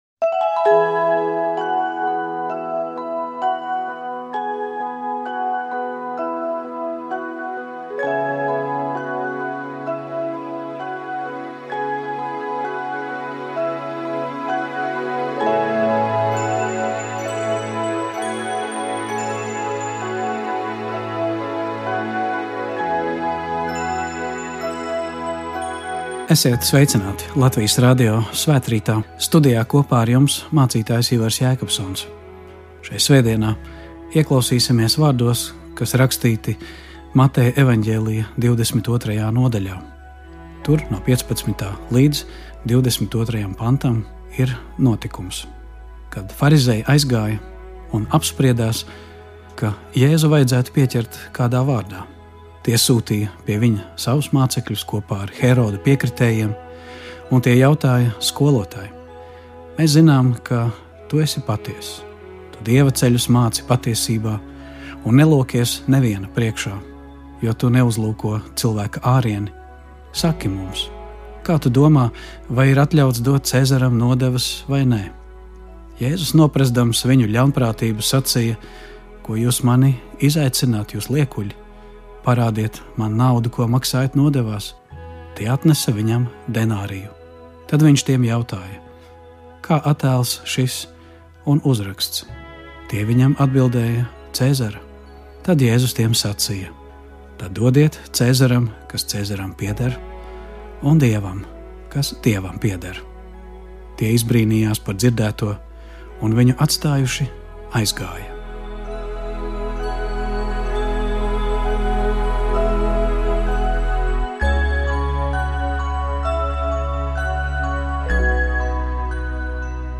Svētrīts. Studijā mācītājs